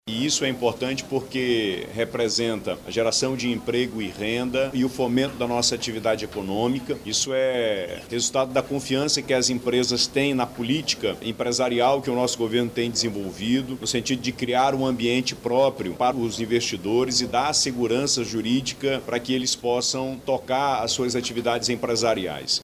O governador do Amazonas, Wilson Lima, que presidiu a reunião, destaca a importância de fortalecer o modelo industrial do Estado.